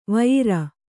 ♪ vayira